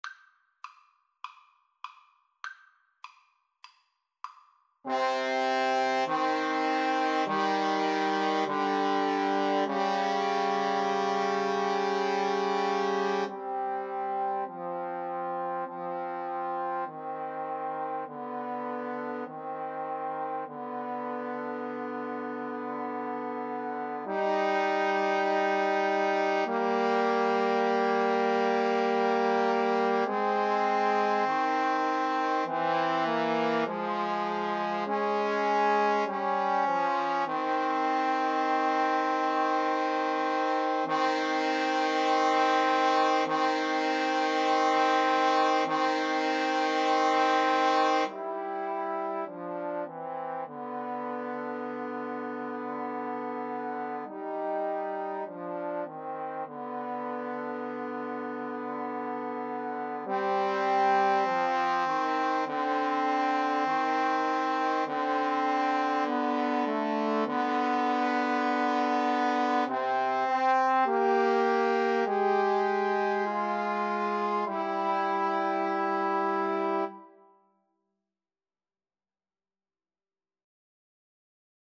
Eb major (Sounding Pitch) (View more Eb major Music for Trombone Trio )
Trombone Trio  (View more Easy Trombone Trio Music)
Classical (View more Classical Trombone Trio Music)